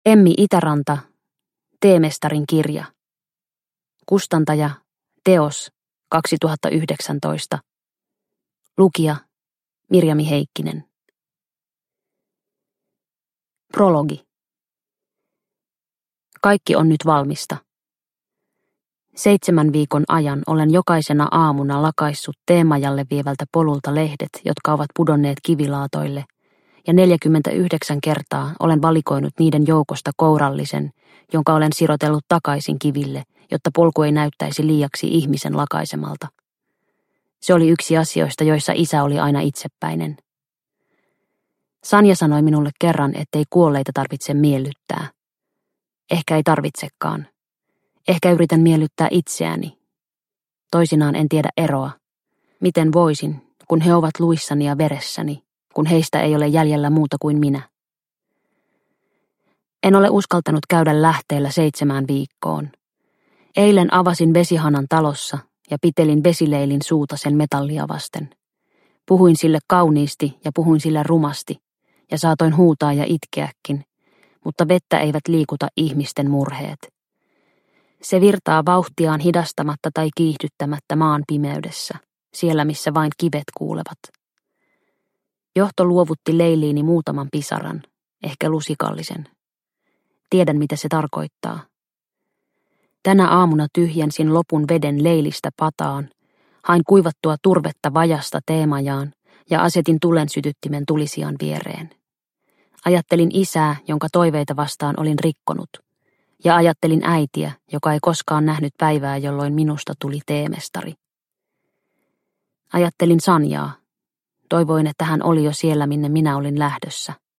Teemestarin kirja – Ljudbok – Laddas ner